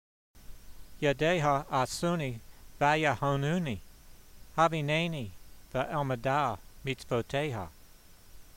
v73_voice.mp3